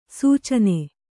♪ sūcane